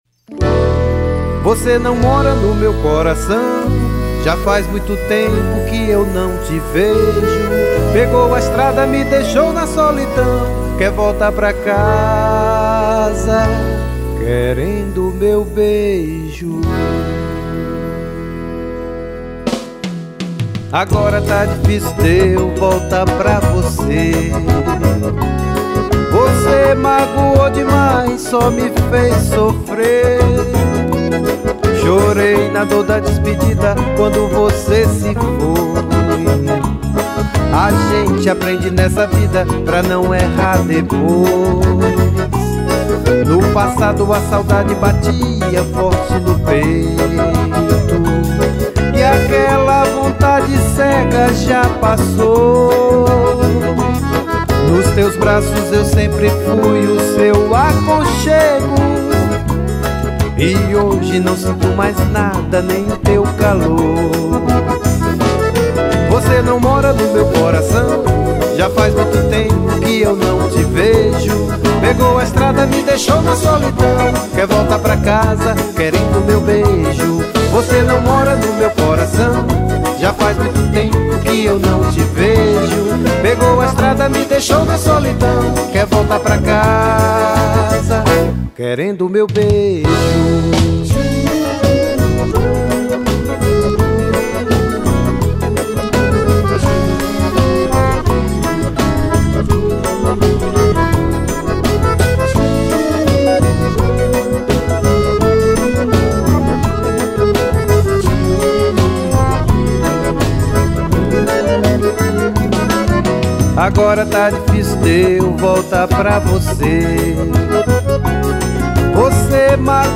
MÚSICA POPULAR NORDESTINA
2313   03:35:00   Faixa: 5    Xote
Acoordeon